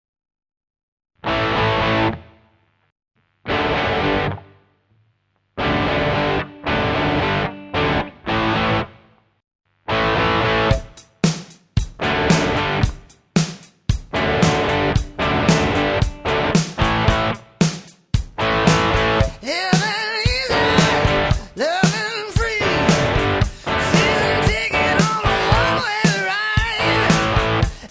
-Preamp a lampe 2x 12ax7, midi
-son chaud et precis